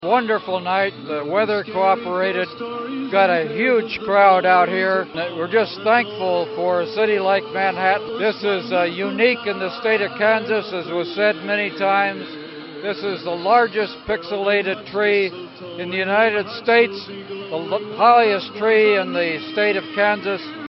KMAN spoke with Manhattan City Mayor Mike Dodson after the lighting to give his comments on the event.